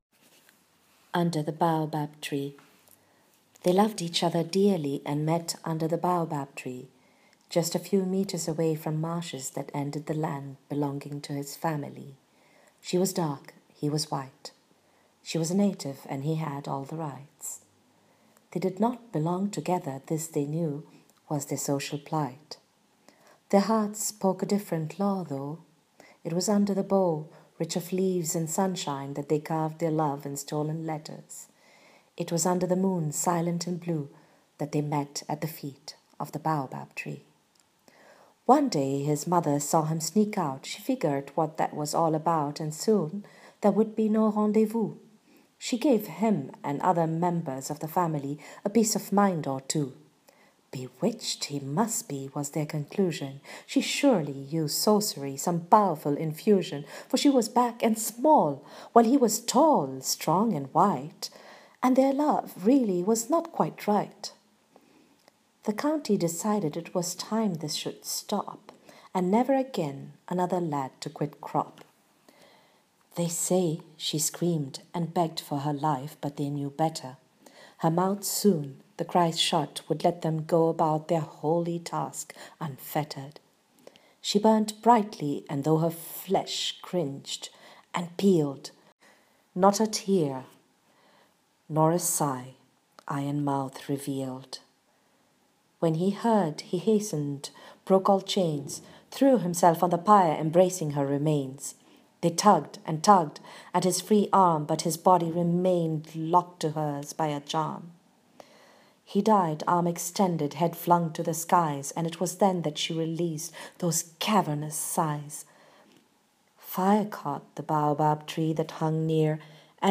Reading of the short story: